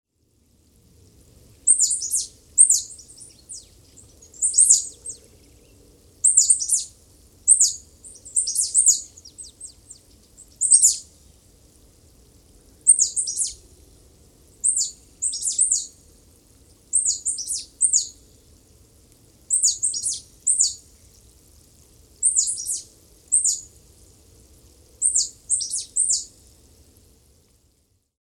Figuinha-do-mangue
Conirostrum bicolor
A voz é bem aguda e pode passar despercebida.
Nome em Inglês: Bicolored Conebill